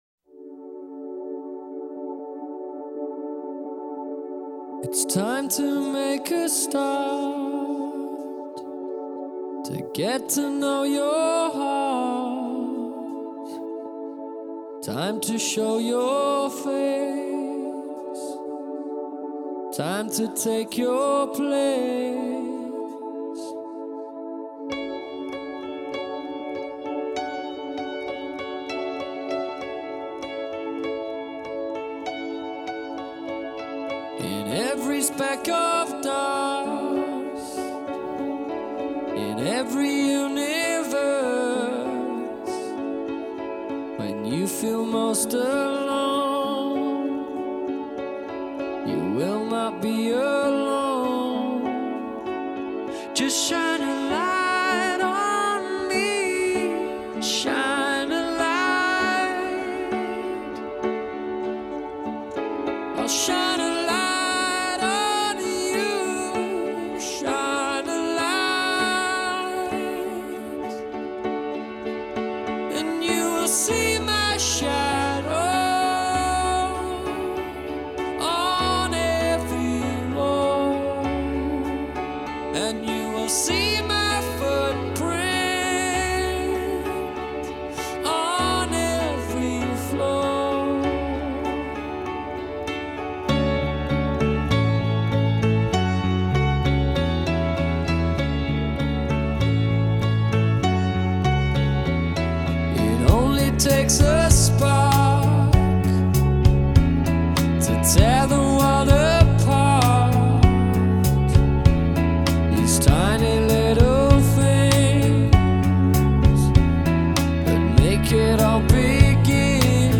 Инди рок Indie rock